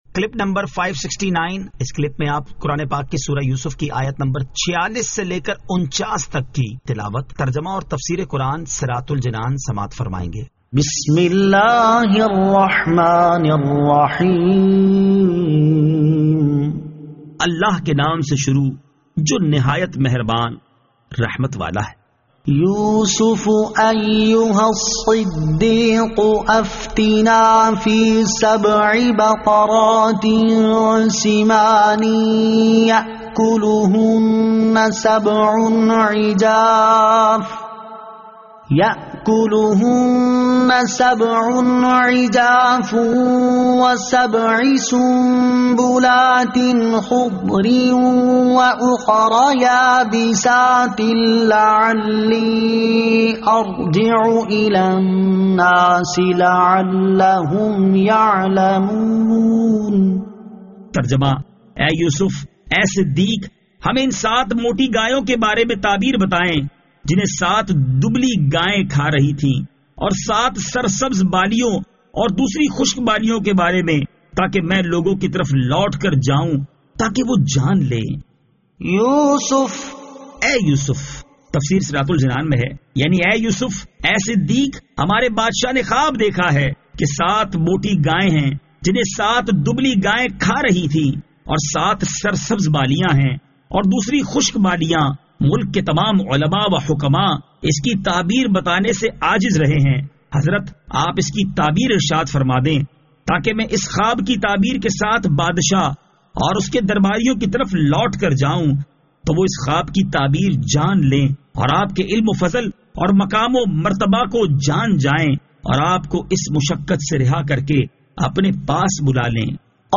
Surah Yusuf Ayat 46 To 49 Tilawat , Tarjama , Tafseer